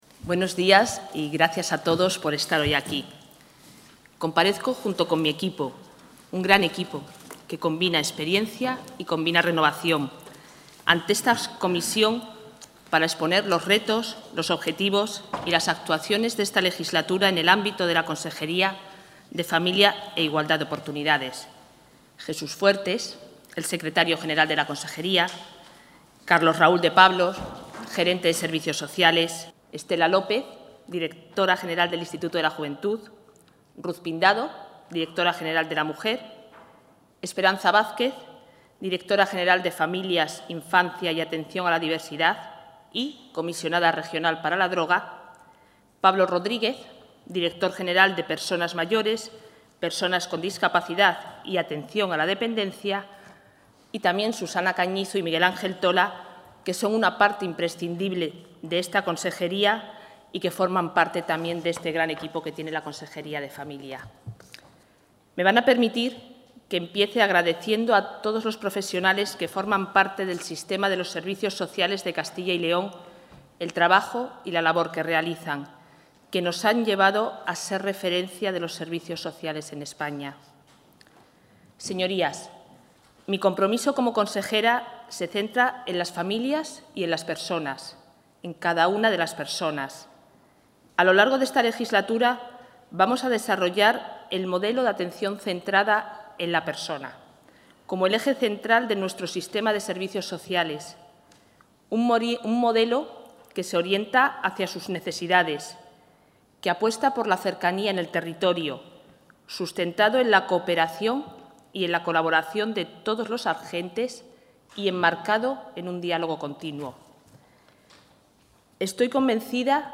La consejera de Familia e Igualdad de Oportunidades, Isabel Blanco, ha comparecido esta mañana en las Cortes, para exponer los principales...